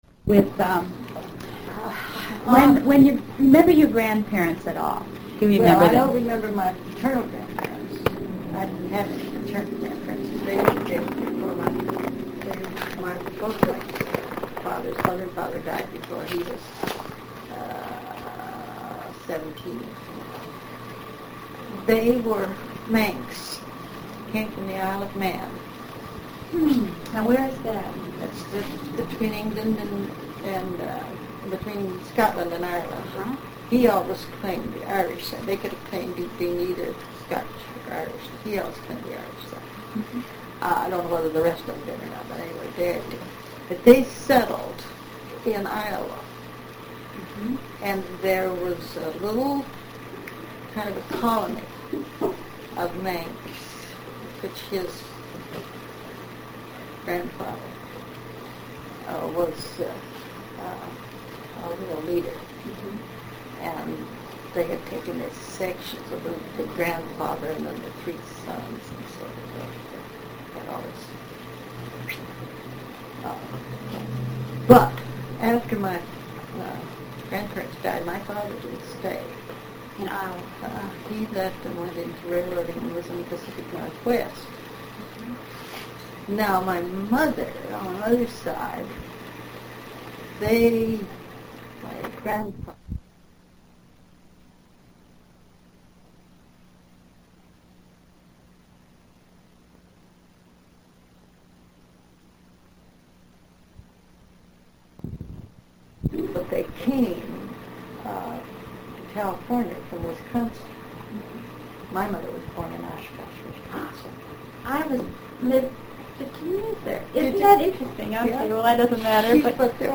The audio quality of this first interview is poor, both because of her tendency to speak in a rather low voice and because of technical difficulties, particularly on the second side of the tape (1b).
Note: the interview begins abruptly with a discussion of family background. There is an interruption in the audio sound in this segment from 1:32 to 1:46.